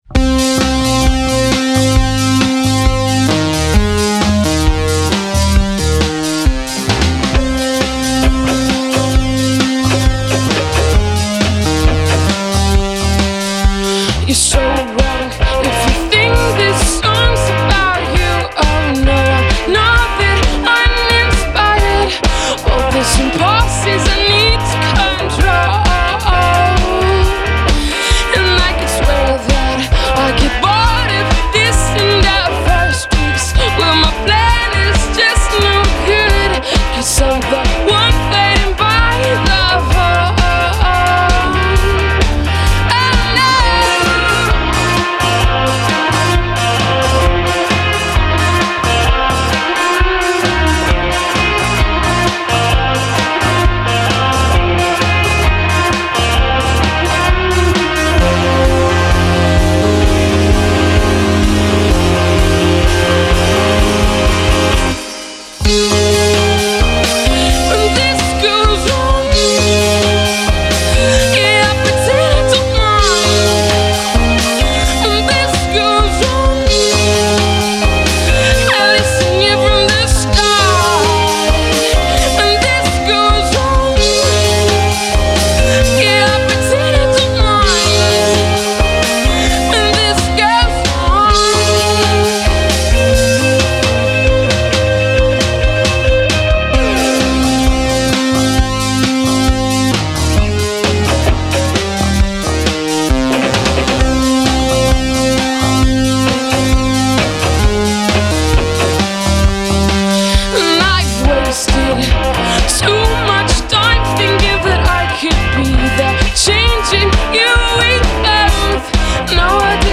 indie / psychedelic / electro